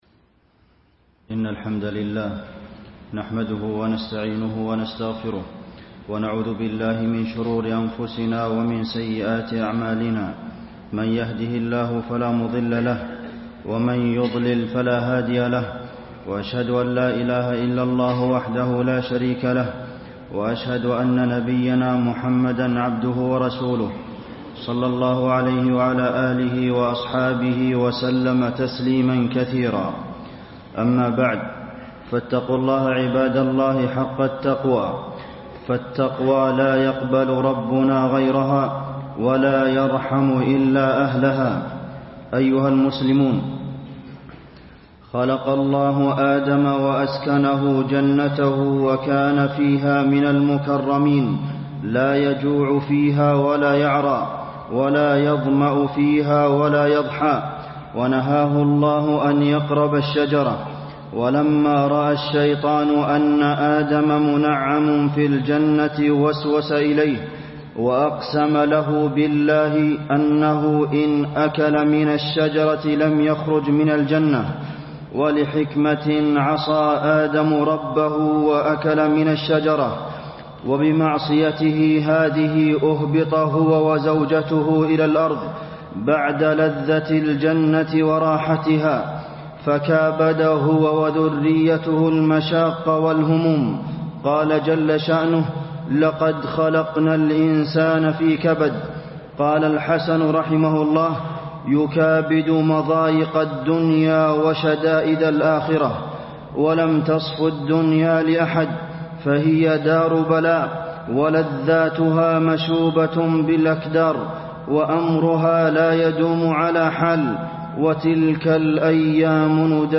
تاريخ النشر ٤ رجب ١٤٣٣ هـ المكان: المسجد النبوي الشيخ: فضيلة الشيخ د. عبدالمحسن بن محمد القاسم فضيلة الشيخ د. عبدالمحسن بن محمد القاسم الصبر على البلاء